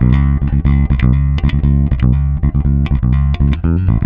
-JP THROB C#.wav